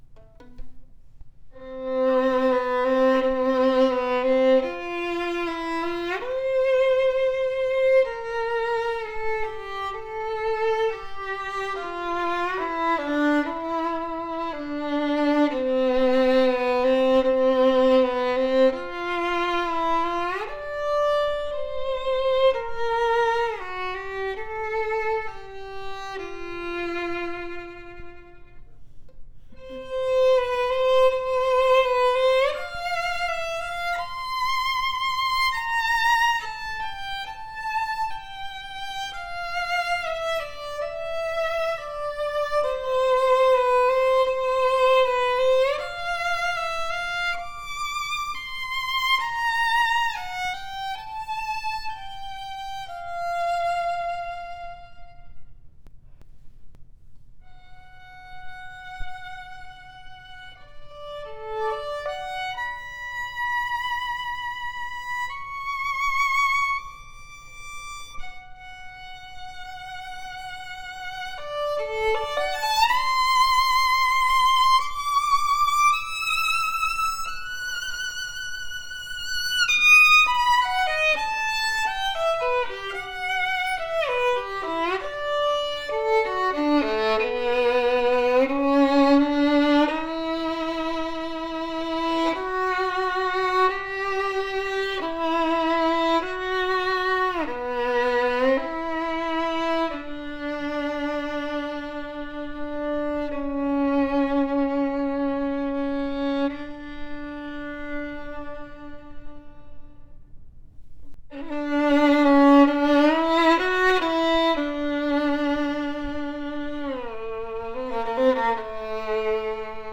A superior “Cannone” Guarneri copy with warm and projective tone, one piece exquisite flame maple, extremely rare at this price range! Professionally set up with upgraded Aubert #7 bridge for optimal resonance and response, superior power and projection that will surprise you at this price range. Ringing higher register that projects well and not overly bright, open and pleasant to hear. Huge and resonant G string with a deep open tone.